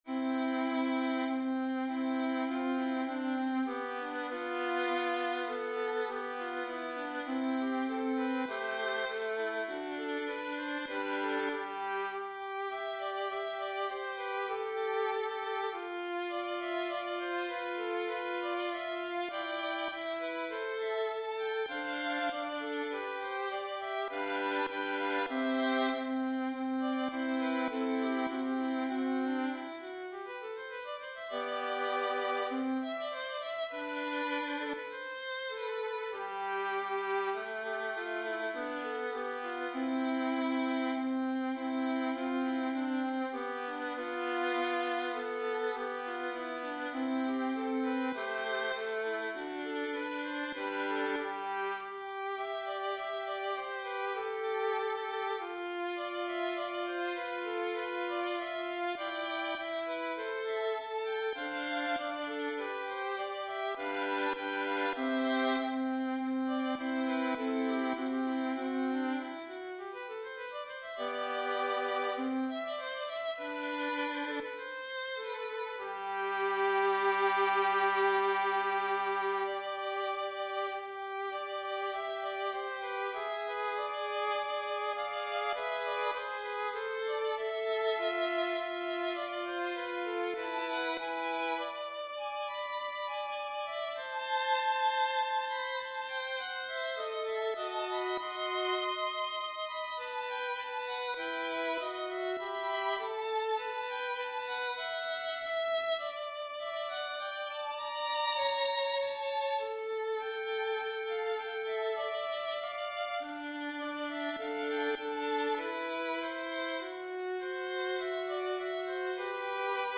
바흐 바이올린 소나타 2번 3악장 > 바이올린 | 신나요 오케스트라